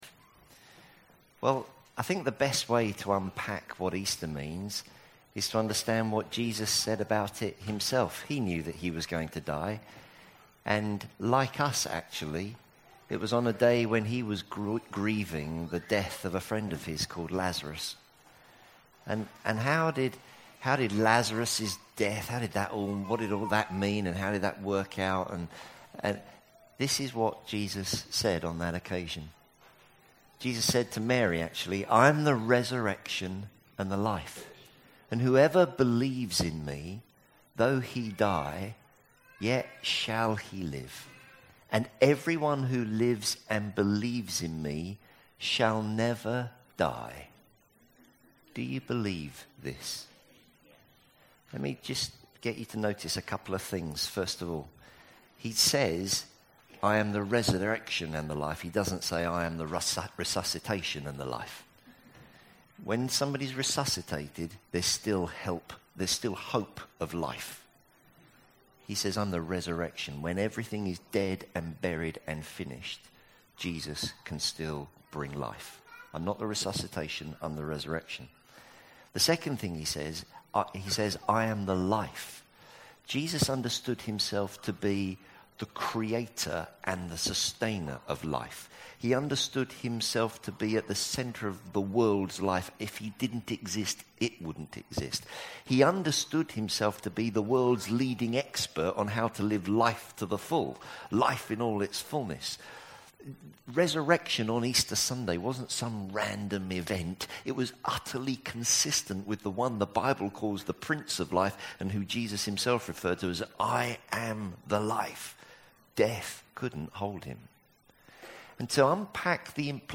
Easter Sunday Baptism Service